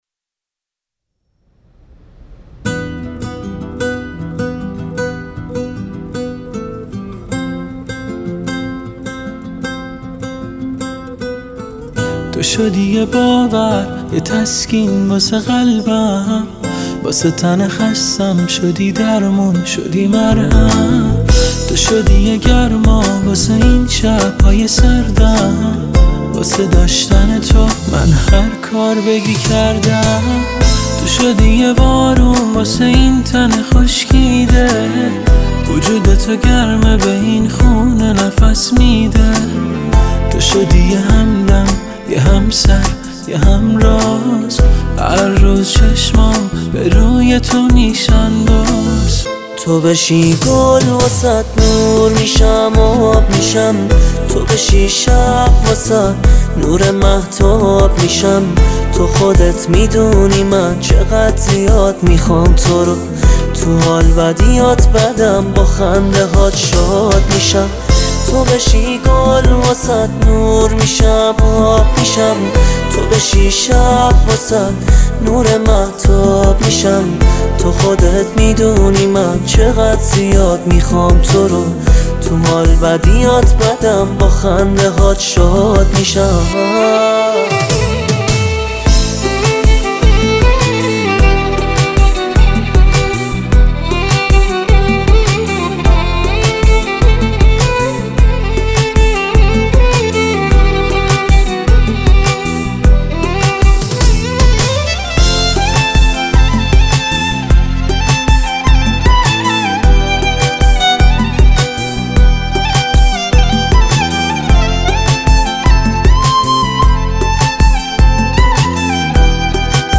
شنیدنی و احساسی